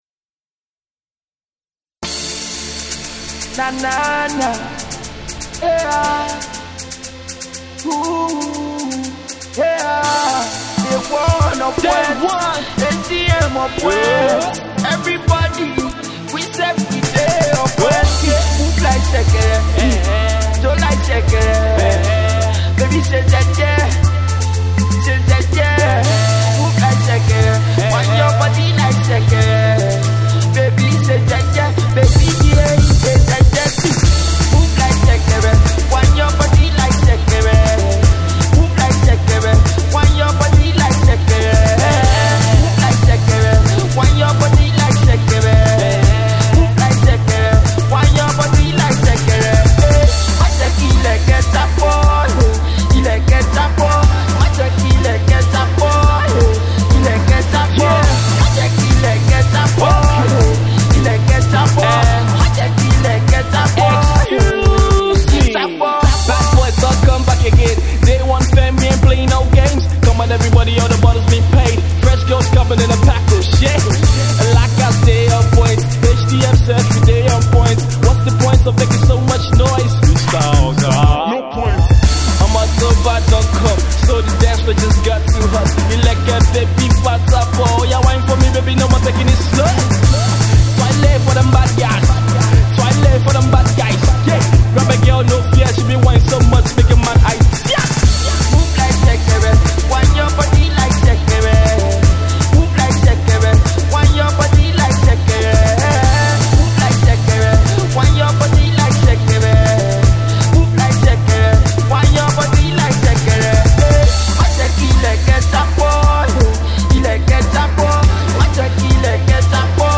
is a rising Nigerian Afro-Pop artist based in Cyprus
beat heavy singles
made to make your body rock